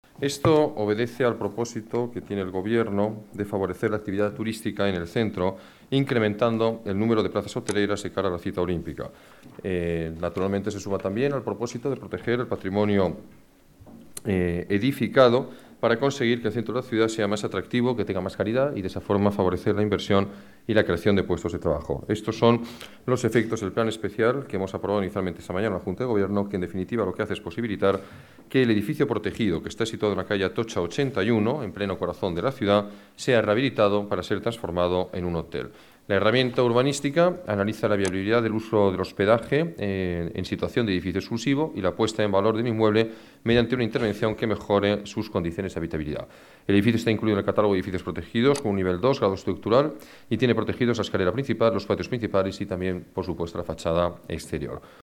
Nueva ventana:Declaraciones del alcalde, Alberto Ruiz-Gallardón: nuevo hotel en la calle Atocha, 81